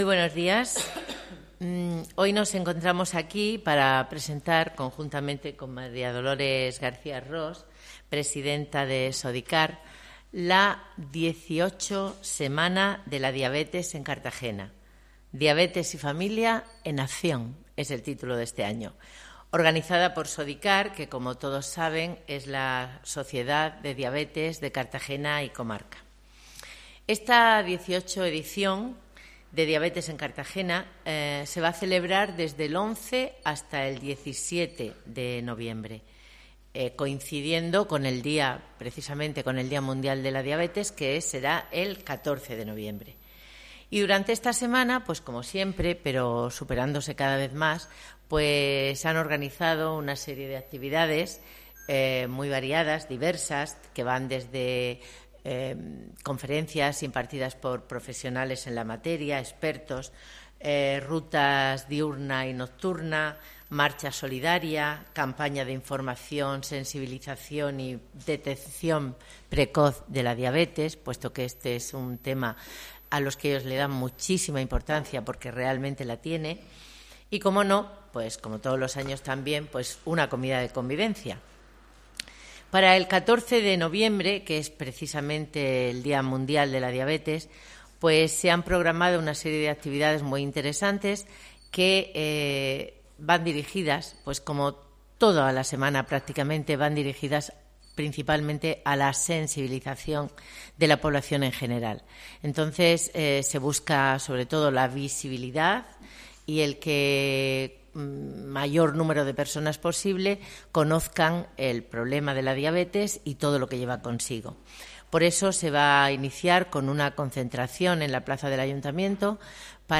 Audio: Presentaci�n de la XVIII Semana de la Diabetes en Cartagena (MP3 - 13,43 MB)